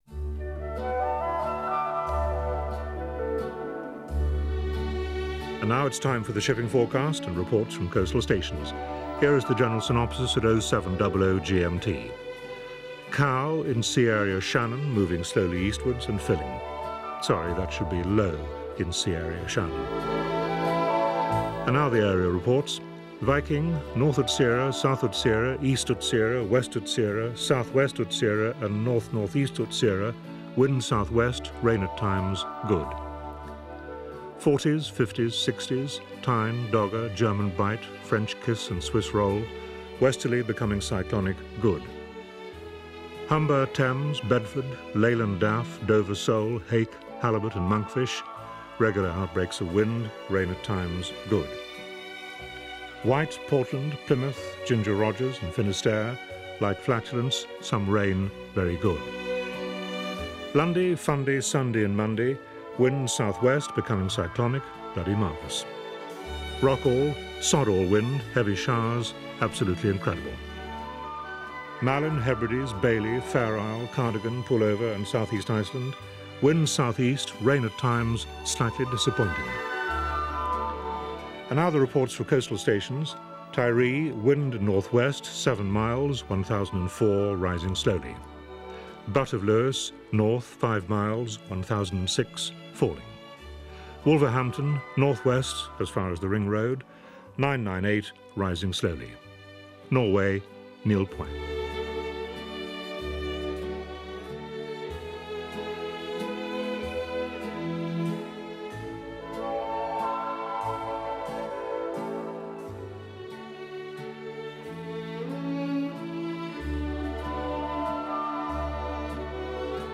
New Edition of the Weather forecast  especially for Eventiders!
Shipping forecast.s48